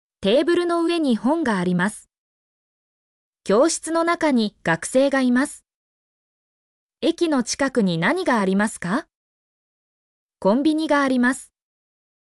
mp3-output-ttsfreedotcom_zd7RqhJX.mp3